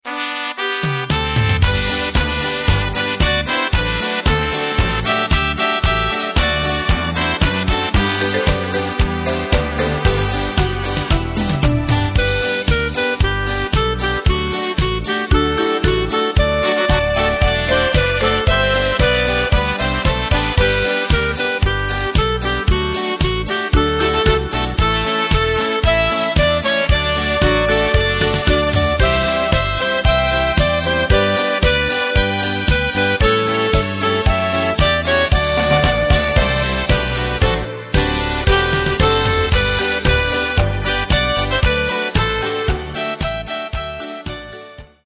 Takt:          4/4
Tempo:         114.00
Tonart:            G
Happy-Polka aus dem Jahr 2006!